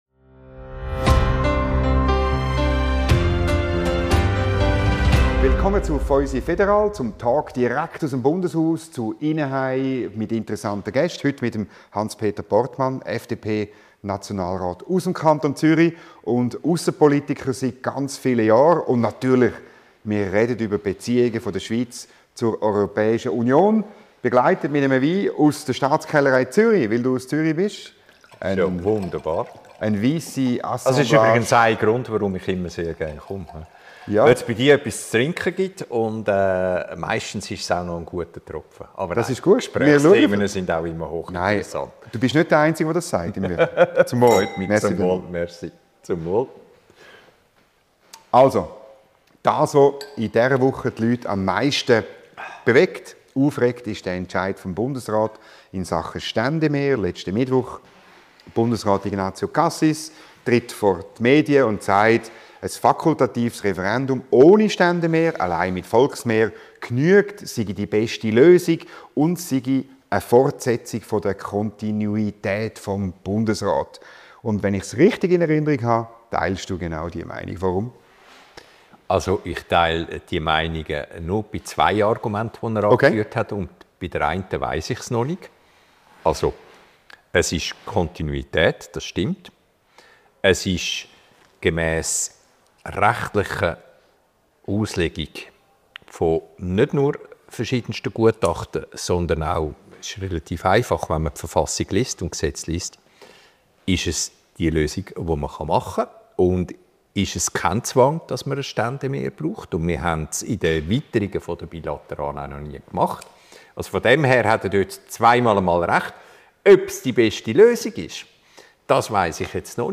Ein Gespräch wie kein anderes: Schweizer Politik und (meist) eine Flasche Wein.